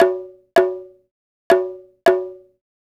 Bongo 05.wav